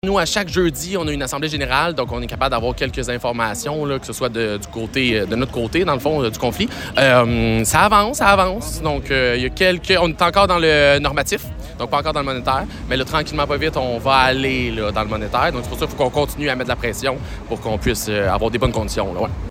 Questionné sur l’état des négociations, voici la réponse d’un des syndiqués